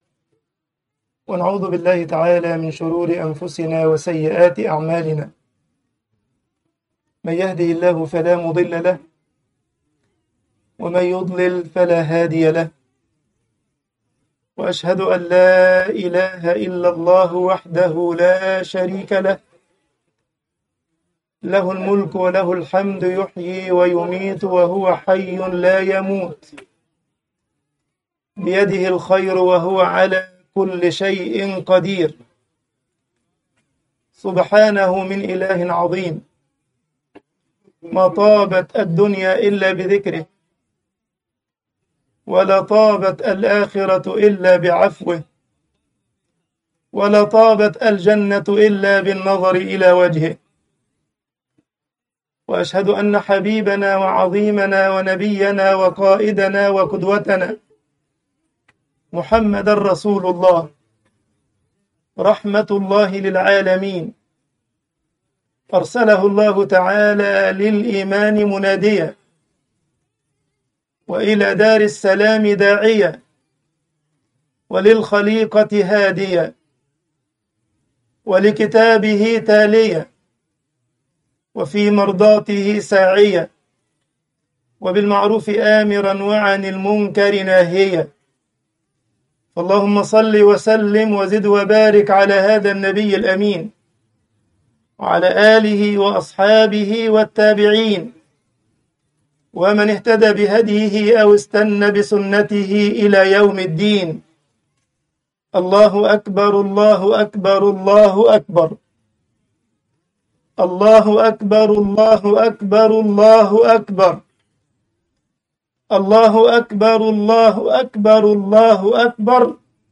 خطبة عيد الفطر فِلَسْطِينُ وَأعْيَادُ الْمُسْلِمِينَ